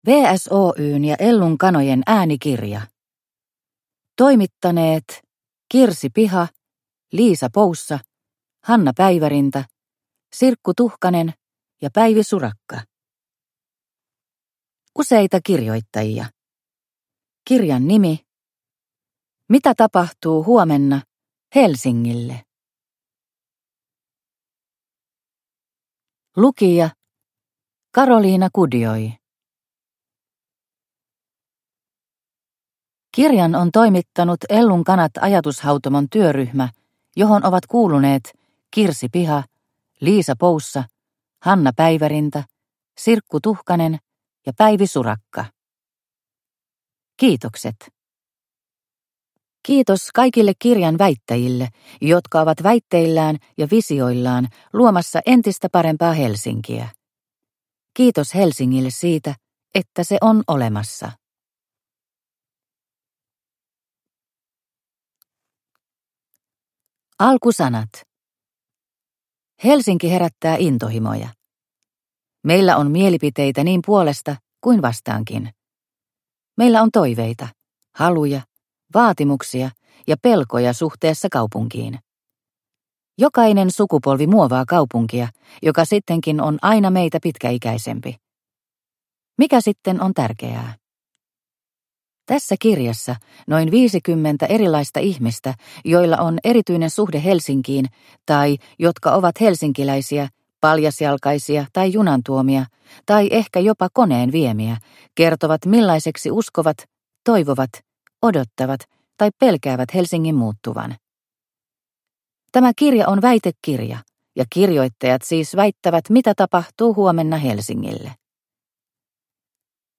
Mitä tapahtuu huomenna Helsingille? – Ljudbok – Laddas ner